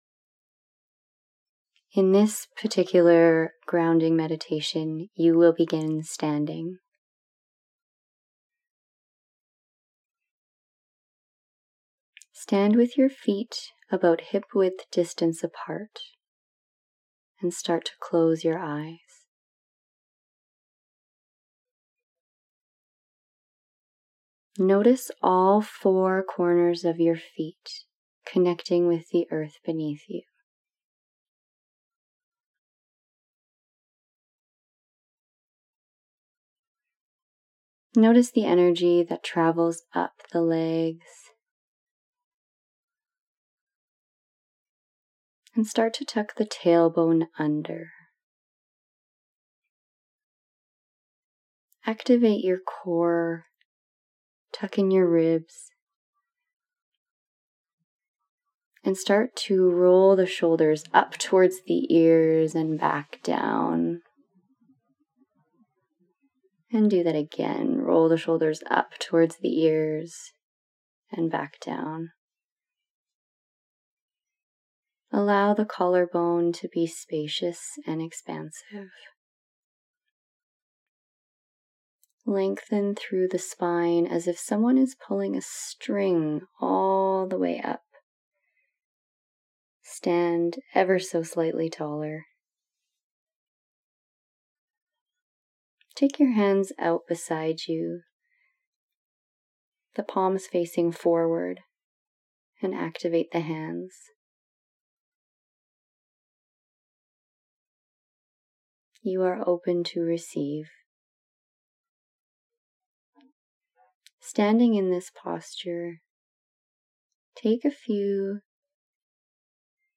In just 4 minutes with this easy guided tadasana meditation, you will powerfully shift your state into empowerment, courage, and deep inner strength.